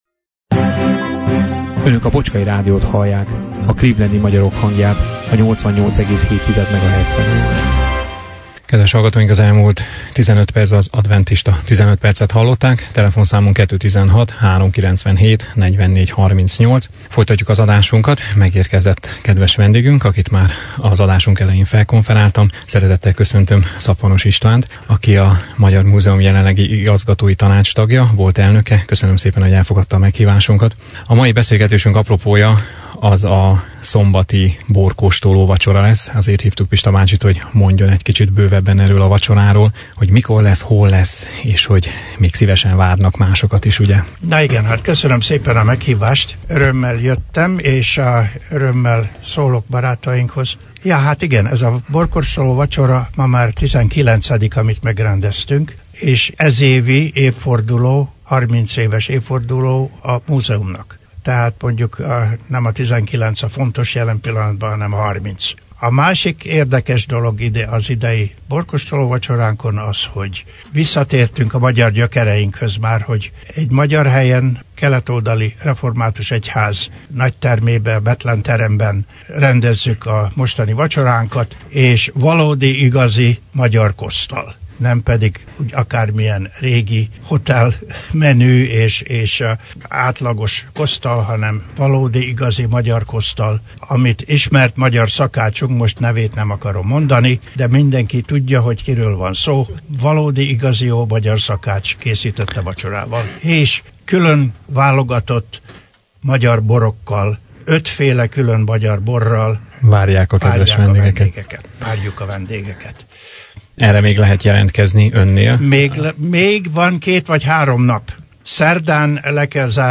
egykori elnöke volt a vendégünk vasárnap, 11-én a stúdióban.